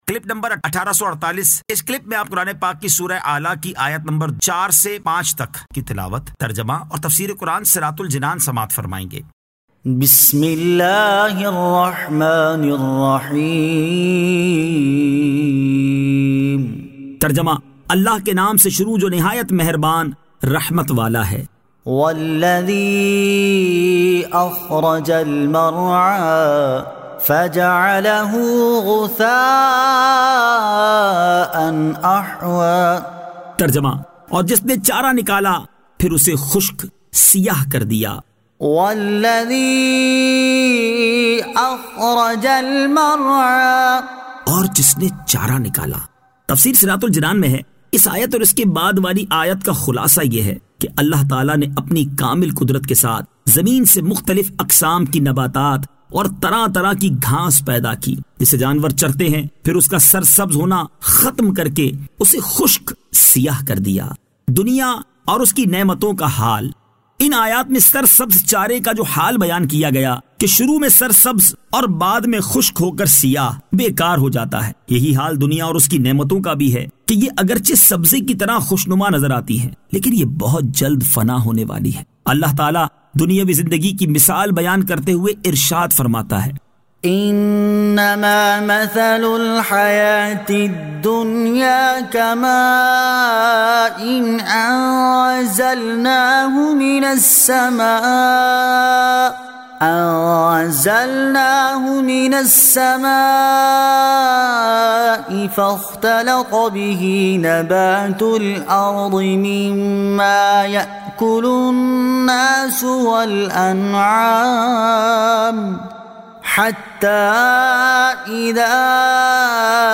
Surah Al-A'la 04 To 05 Tilawat , Tarjama , Tafseer